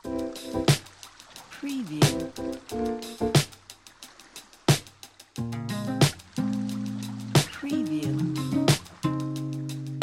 مجموعه سمپل های لوفای | بیش از 3 هزار سمپل سبک لوفای
مجموعه سمپل های لوفای | انواع لوپ های لوفای ، لوپ ملودیک لوپ درام و انواع وان شات ها مخصوص سبک لوفای با کیفیت بسیار بالا | بیش سه هزار سمپل در یک پکیج
demo-lofi.mp3